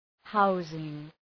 Προφορά
{‘haʋzıŋ}